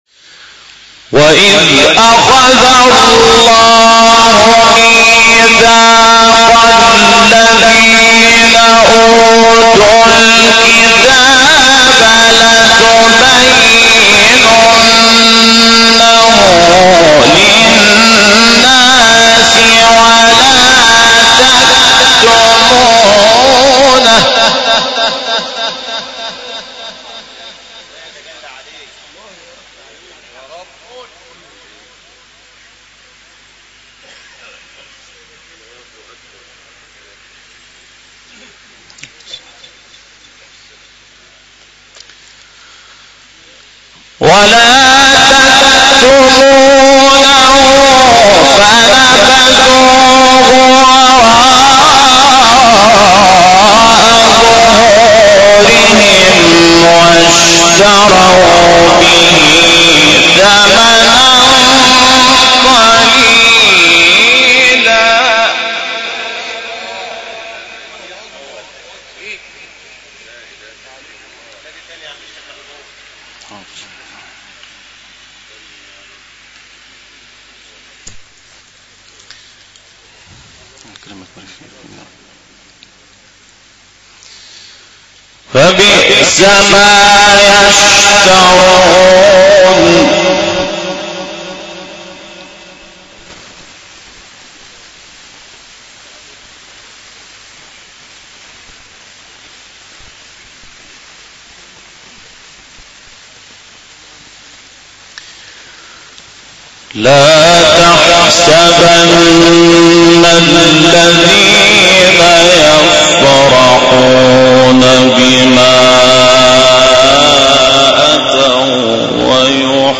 سوره: آل عمران آیه: 187-191 استاد: محمود شحات مقام: بیات قبلی بعدی